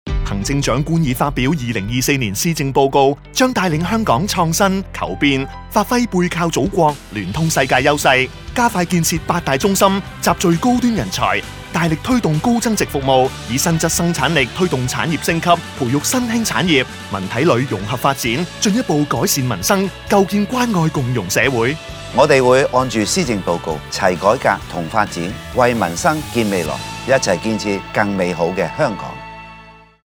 電台宣傳廣播